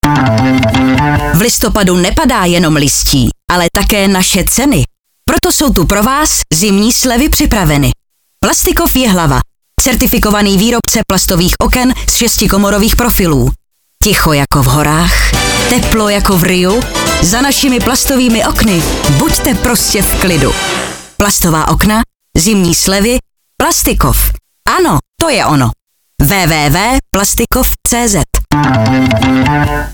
Slogan na radiu Vysočina č.5